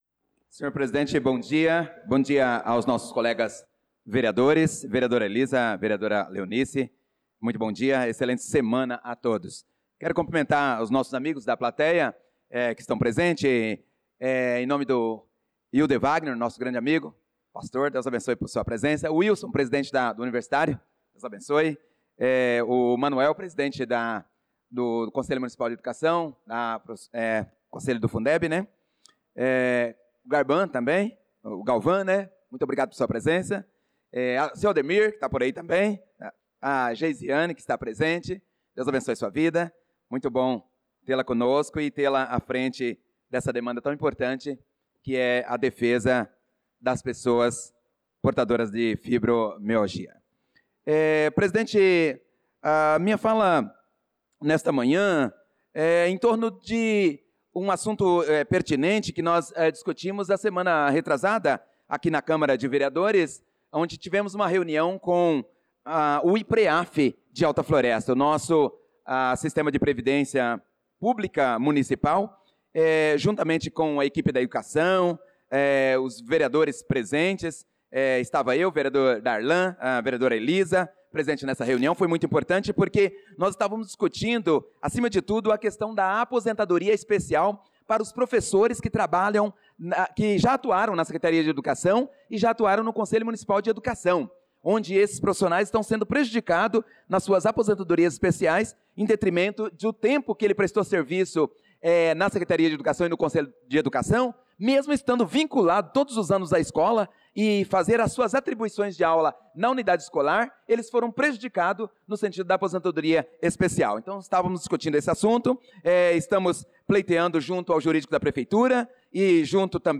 Pronunciamento do vereador Prof. Nilson na Sessão Ordinária do dia 12/05/2025